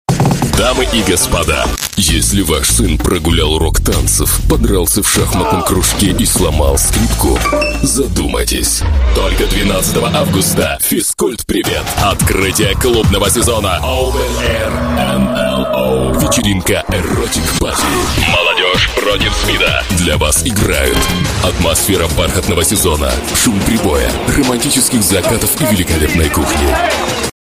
Voice Samples: Voice Sample 02
male